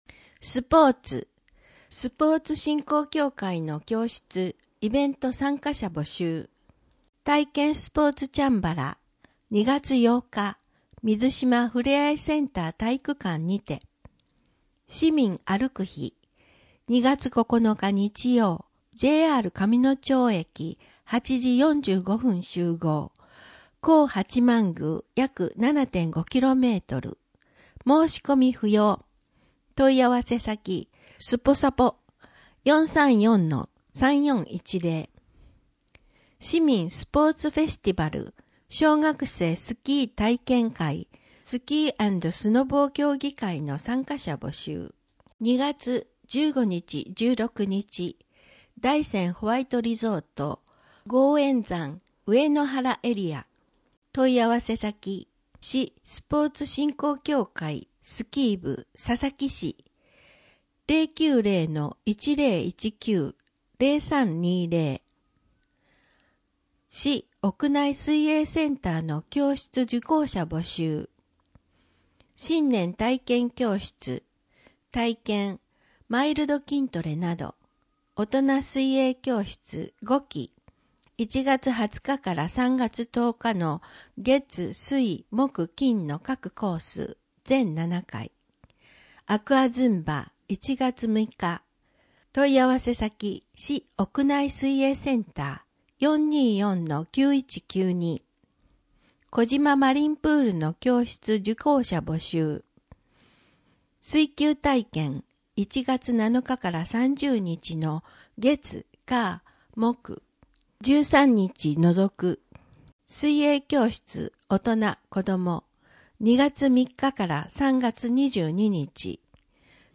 2025年広報くらしき1月号（音訳版）